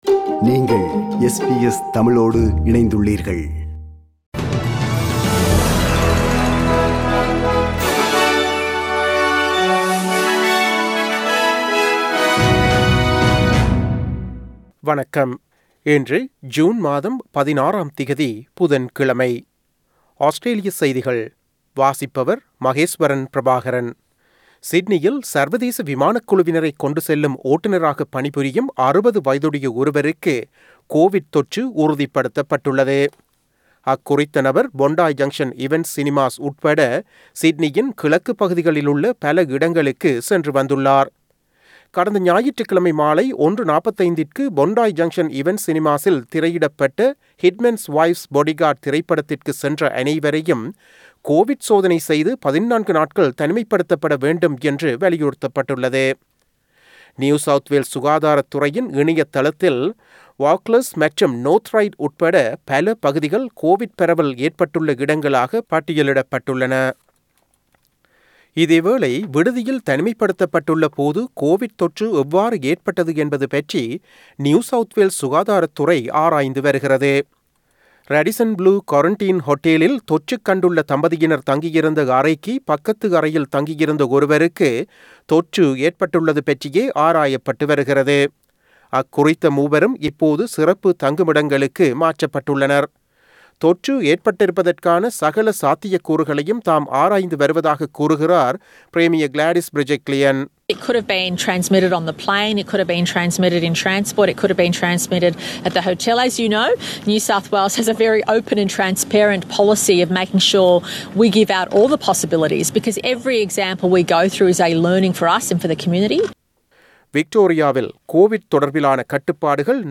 Australian news bulletin for Wednesday 16 June 2021.